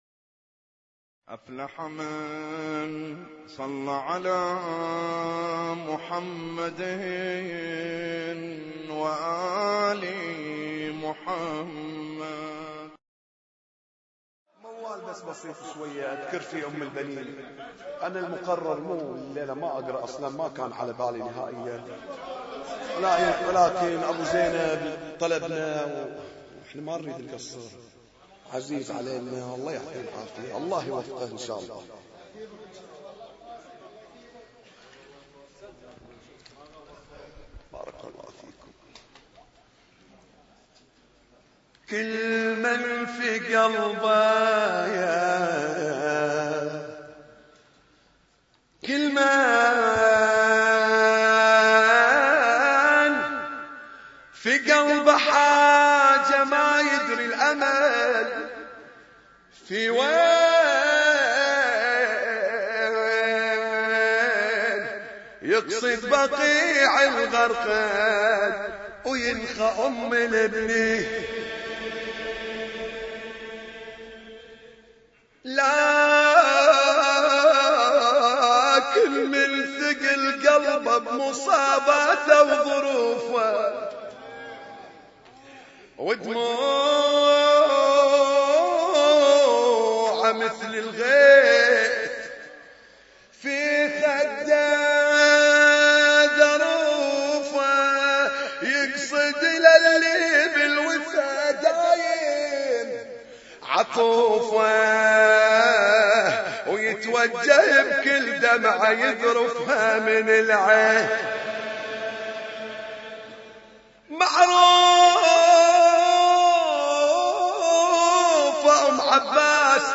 اسم التصنيف: المـكتبة الصــوتيه >> الصوتيات المتنوعة >> النواعي